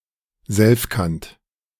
Selfkant (German: [ˈzɛlfkant]